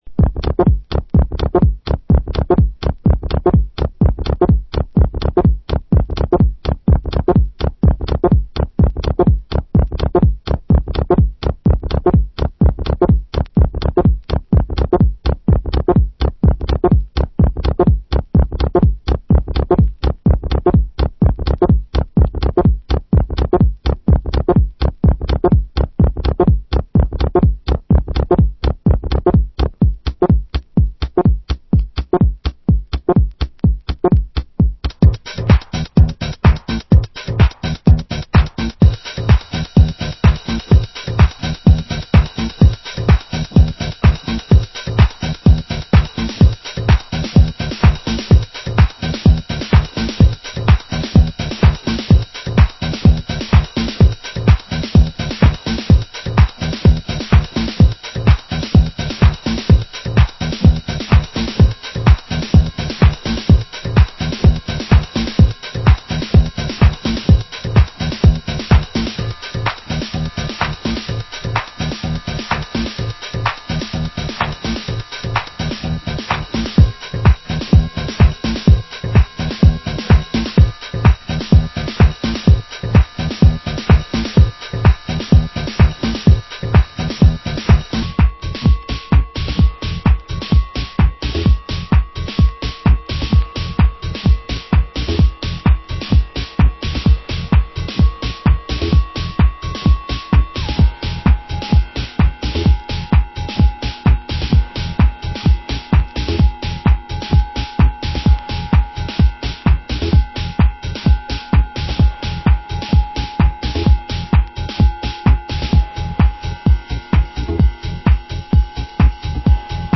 Genre: Minimal